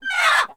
duck_03.wav